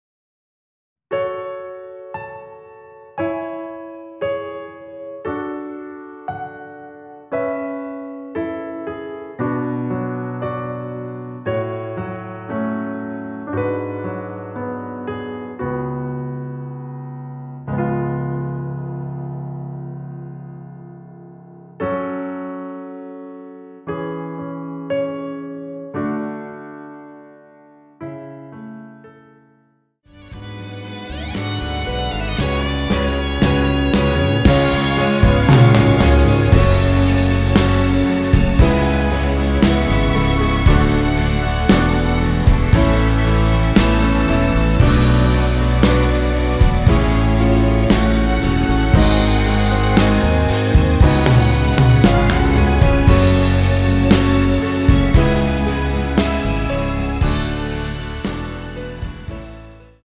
원키에서(-1)내린 MR입니다.
F#m
앞부분30초, 뒷부분30초씩 편집해서 올려 드리고 있습니다.